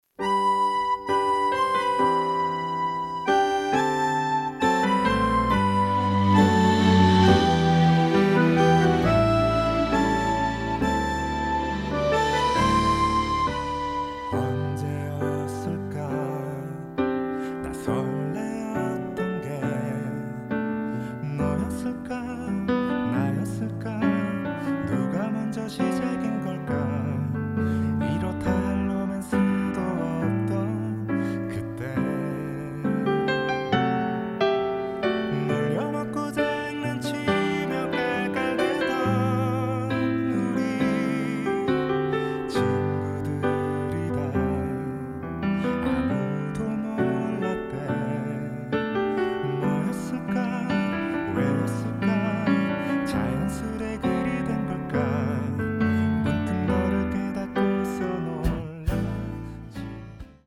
음정 원키 4:27
장르 가요 구분 Voice Cut
Voice Cut MR은 원곡에서 메인보컬만 제거한 버전입니다.